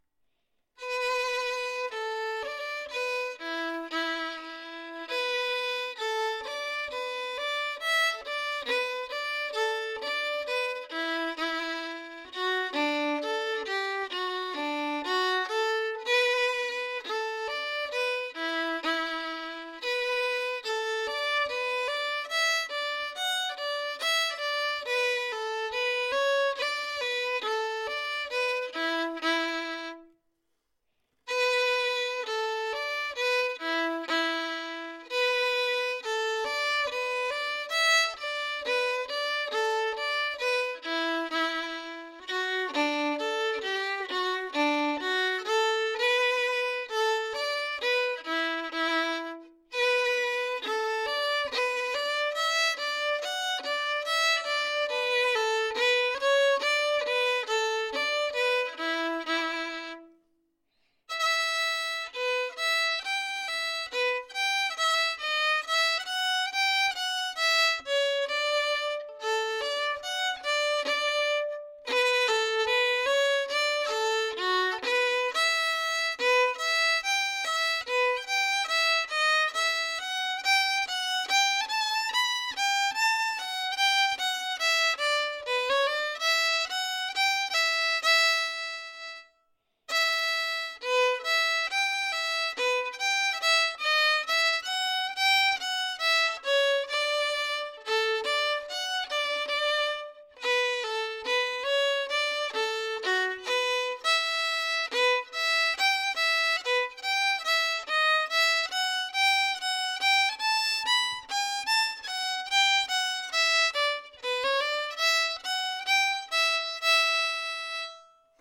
The-Road-To-Glinn_slow.mp3